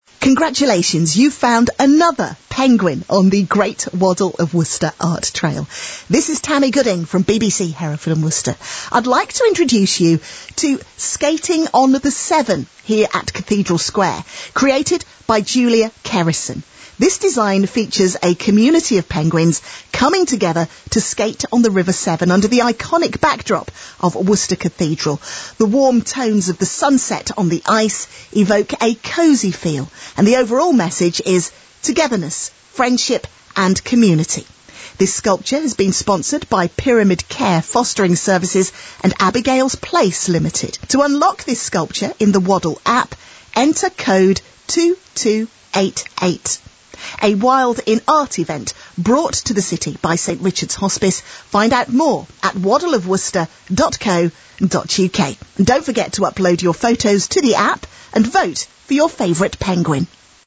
Audio read by: